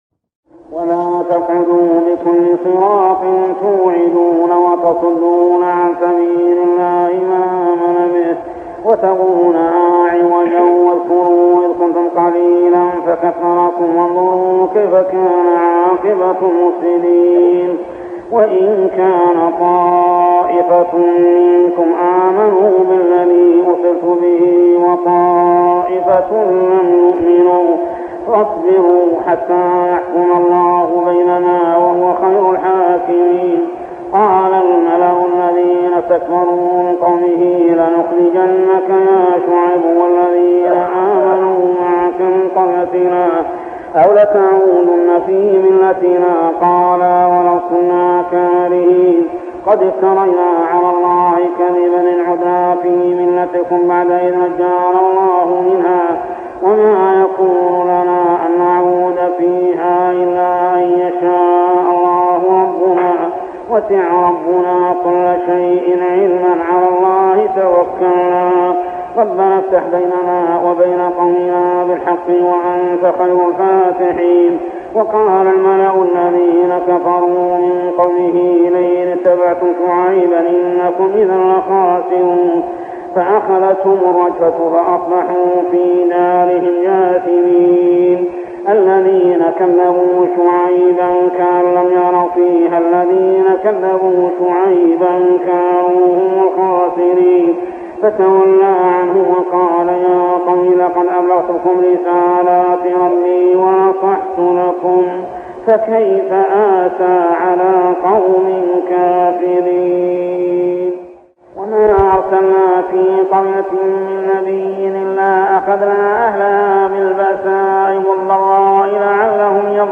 صلاة التراويح عام 1403هـ سورة الأعراف 86-154 ( الآيات 130-141 مفقودة ) | Tarawih prayer Surah Al-A'raf > تراويح الحرم المكي عام 1403 🕋 > التراويح - تلاوات الحرمين